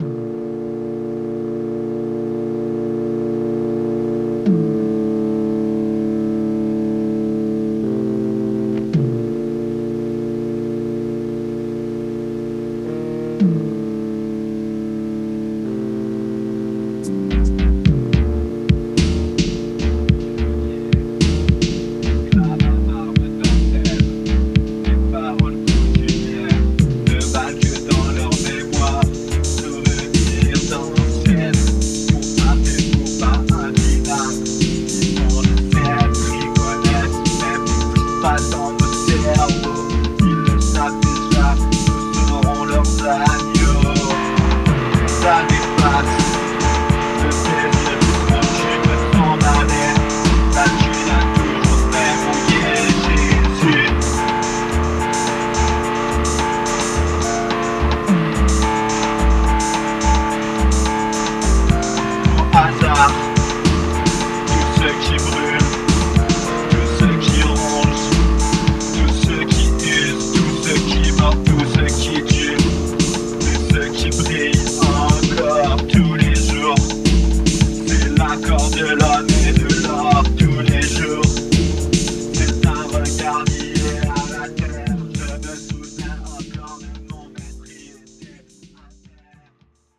supplier of essential dance music
Electronix Wave Italo